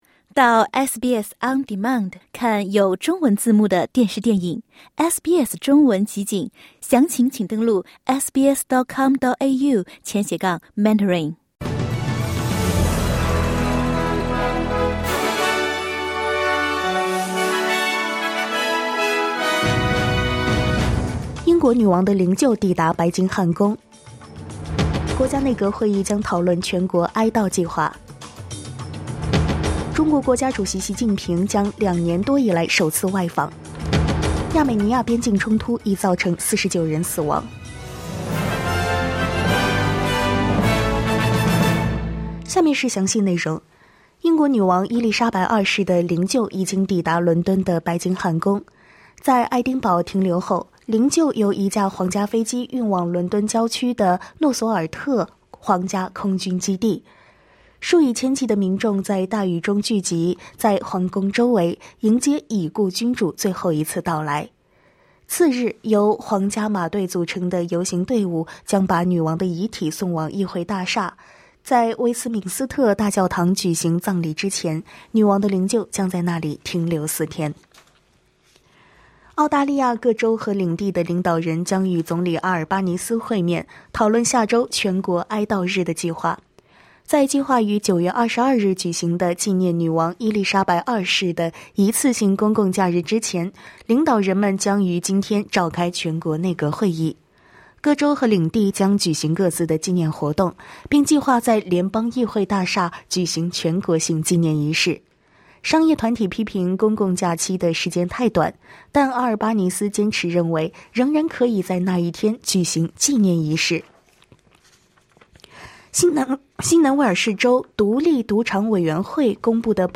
SBS早新闻（9月14日）
请点击收听SBS普通话为您带来的最新新闻内容。